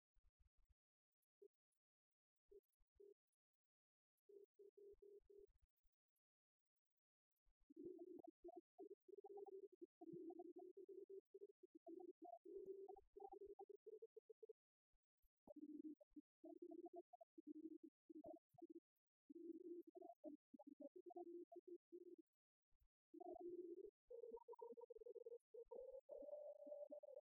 concert du 1er mars 1997 à la Maison de retraire Saint-Martin
Genre strophique
Chorale des retraités à la maison de retraite
Pièce musicale inédite